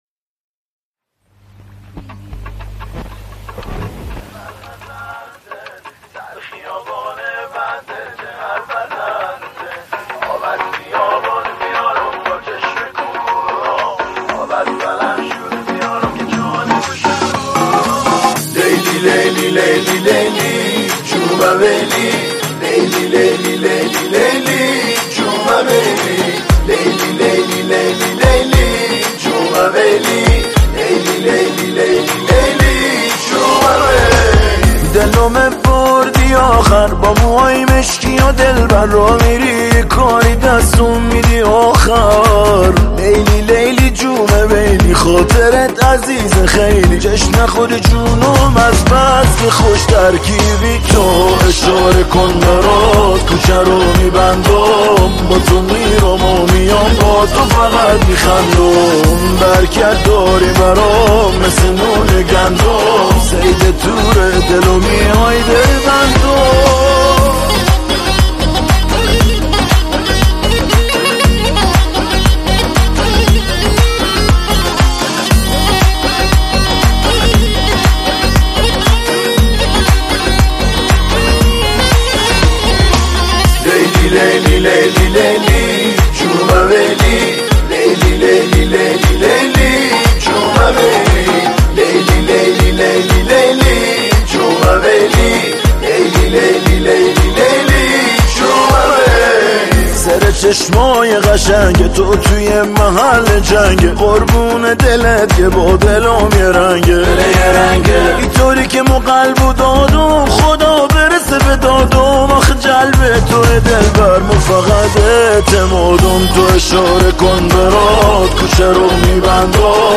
این آهنگ یک عاشقانه بندری شاد است.